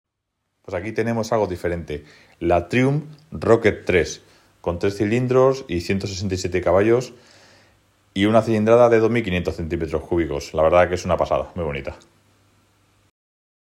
Triumph Rocket 3, 2500cc 😅 Sound Effects Free Download
Triumph Rocket 3, 2500cc 😅 sound effects free download